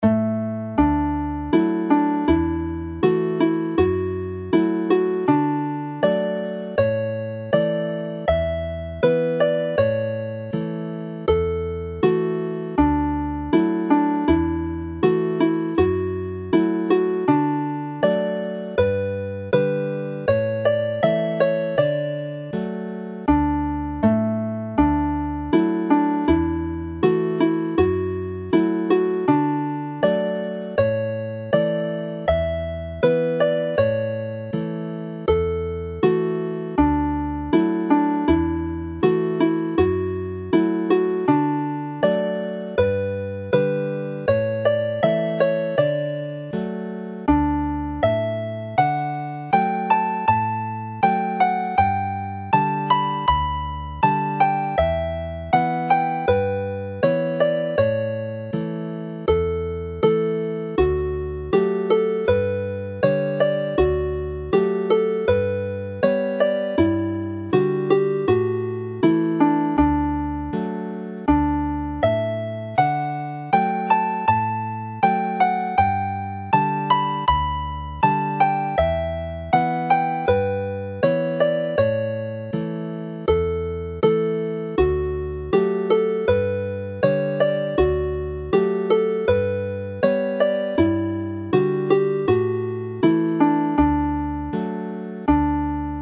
This set is for that day and the St David's Day procession. This set comprises three marches:
Although in the key of A minor, it is a lively tune.
Ymdaith Dydd Gŵyl Dewi is a jolly march to conclude the set.